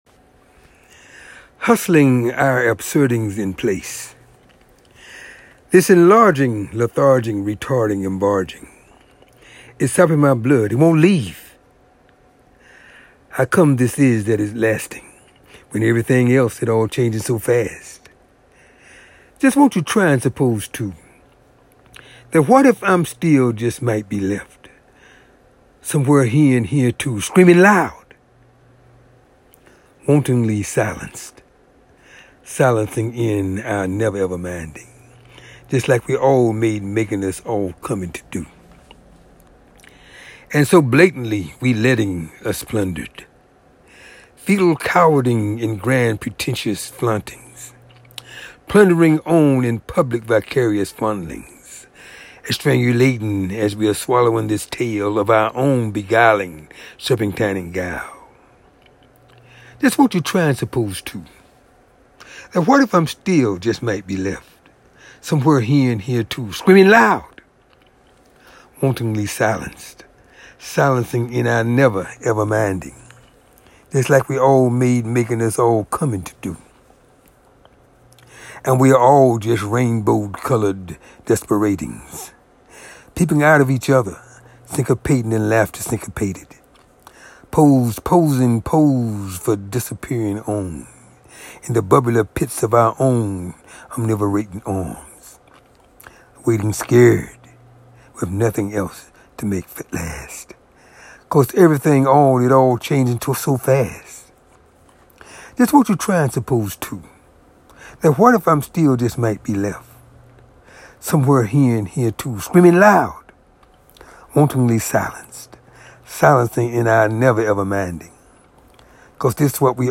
hustling our absurdings in place (a tonal drawing written in poetic form)